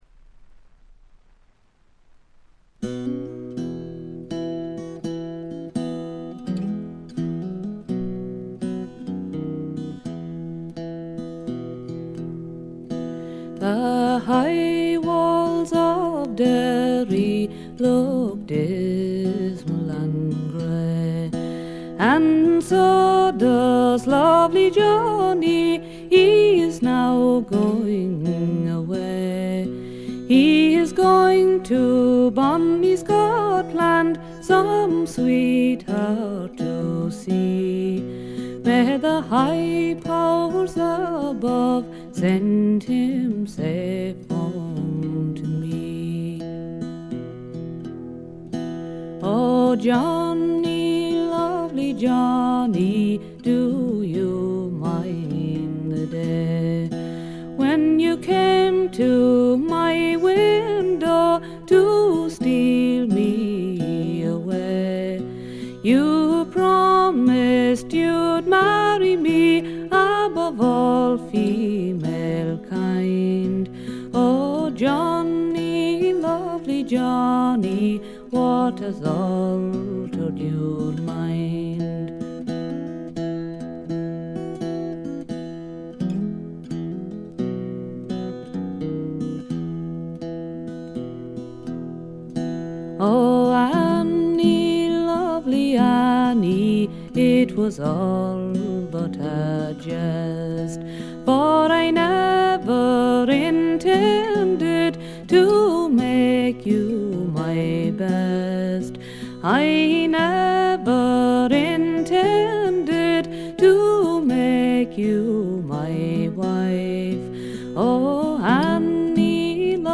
ほとんどノイズ感無し。
声自体が重層的で深いんですよね。
試聴曲は現品からの取り込み音源です。